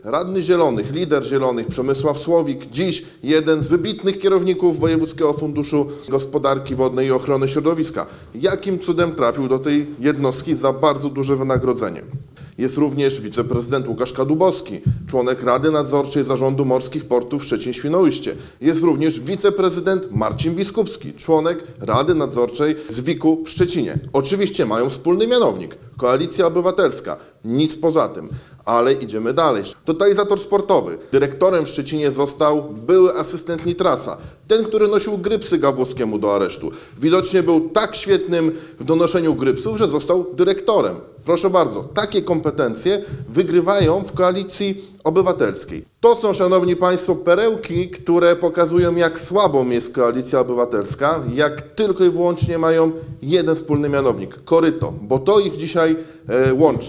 Podczas konferencji prasowej Krzysztof Romianowski, radny miejski z ramienia PiS, oskarżył obecną ekipę rządzącą o kolesiostwo i obsadzanie stanowisk w miejskich spółkach osobami powiązanymi z władzami miasta.